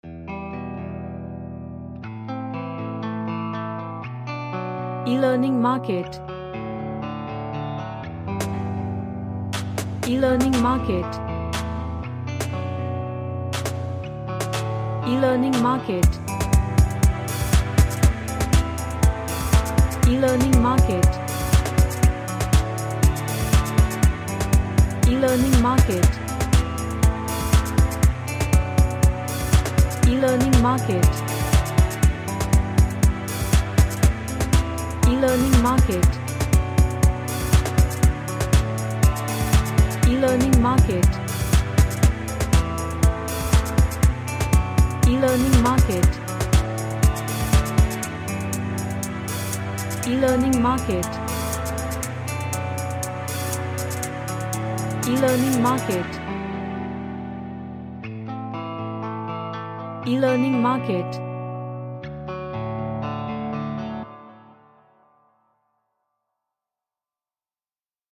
A nice electric guitar Chords played in a melodical style.
Emotional